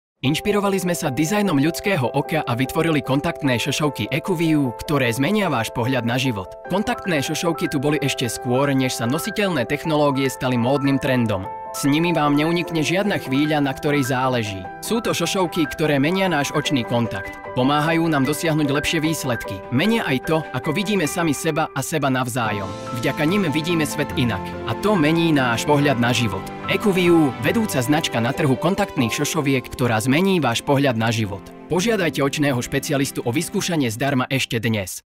Profesinálny mužský VOICEOVER v slovenskom jazyku